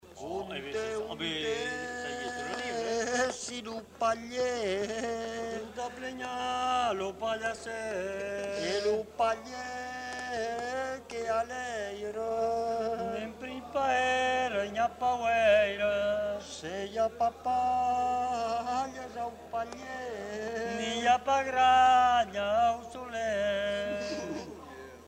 Lieu : Saint-Sever
Genre : chant
Effectif : 2
Type de voix : voix d'homme
Production du son : chanté
Notes consultables : Les deux hommes chantent en alternance.